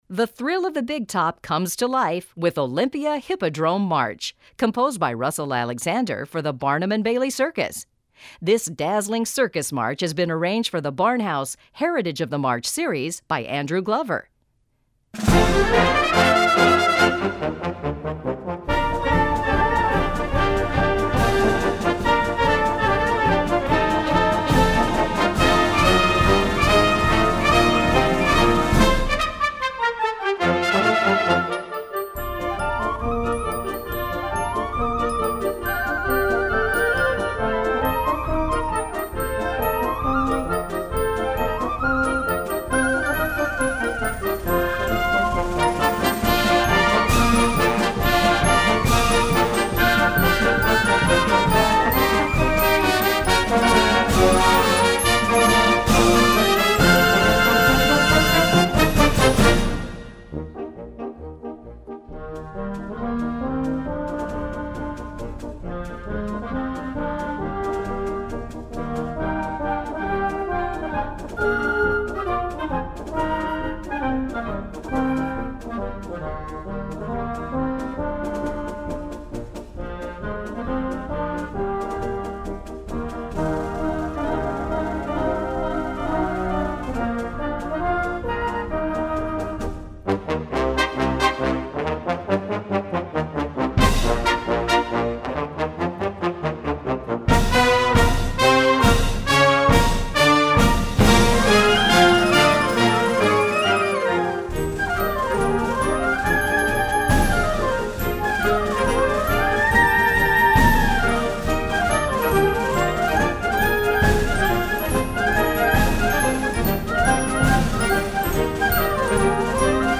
Gattung: March
Besetzung: Blasorchester
This classic circus march